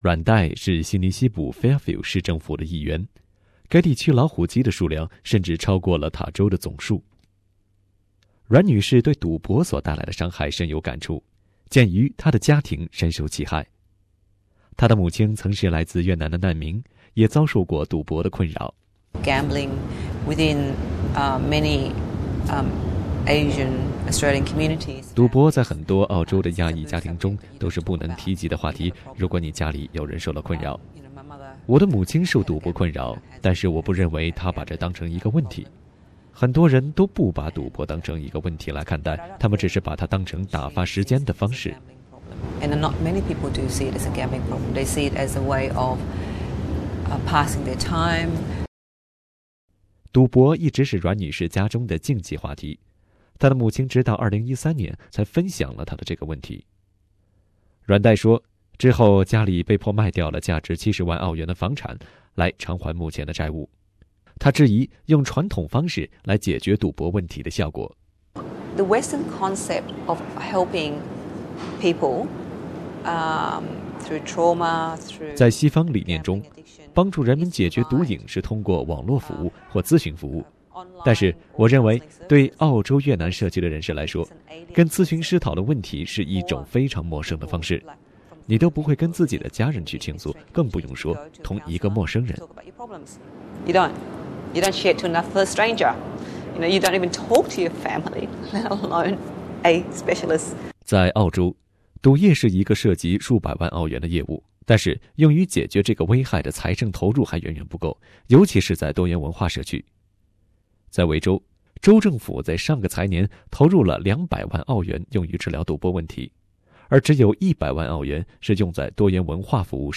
Source: AAP SBS 普通话电台 View Podcast Series Follow and Subscribe Apple Podcasts YouTube Spotify Download (2.08MB) Download the SBS Audio app Available on iOS and Android 他们被称为赌博问题的隐性受害者，他们来自澳洲的移民社区，不再主流服务的范围之内。